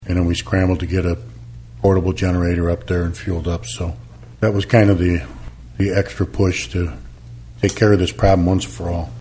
After years of planning for one, a Caterpillar power generator will be installed by Ottawa’s north side water tower. Mayor Dan Aussem says a power outage affected the police, fire, and public works departments last week.